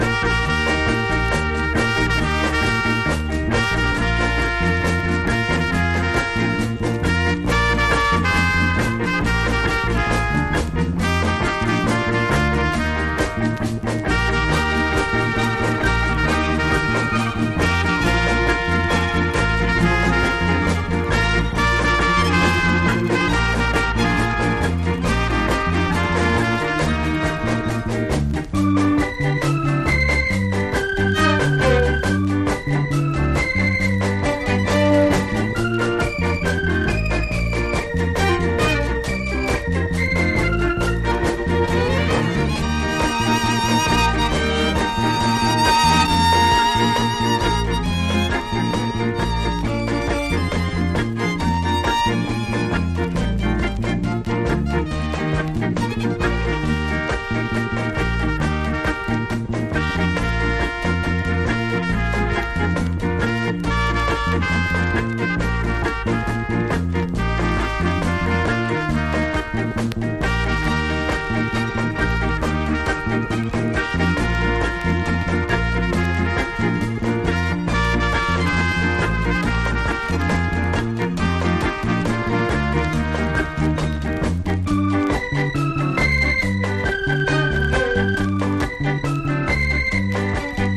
カリビアン・ロックステディ〜アーリィ・レゲエなナイス・アレンジ！